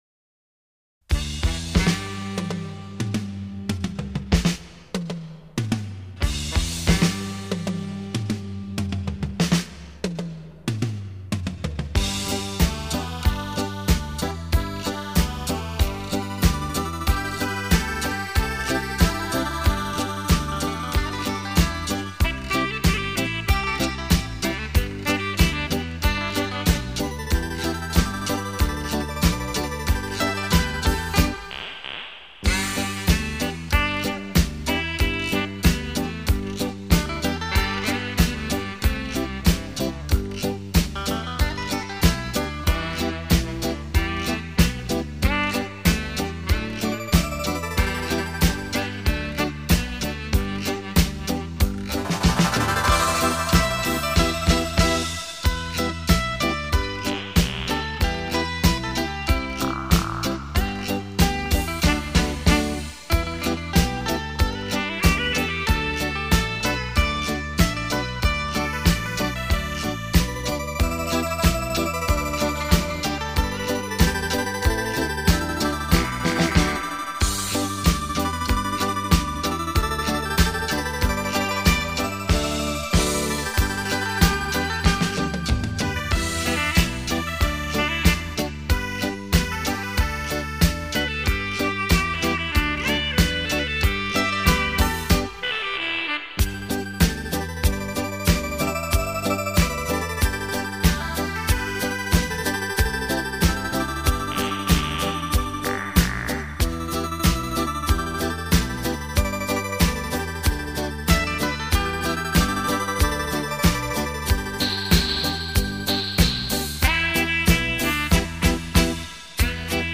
閒適優雅的音符，完美傳真的音質，呈獻在您的耳際
優美動聽的舞蹈旋律讓人沉醉其中...